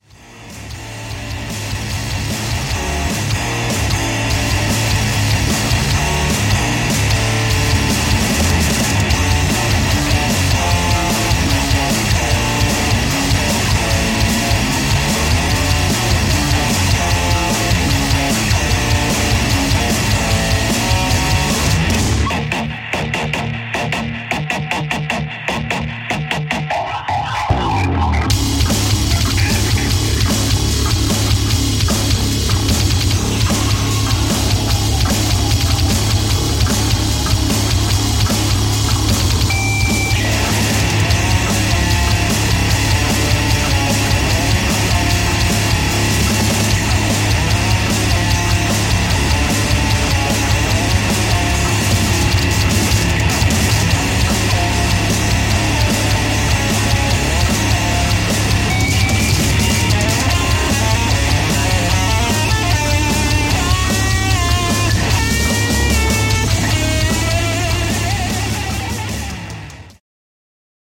The SW Michigan Power Trio.